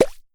drill.mp3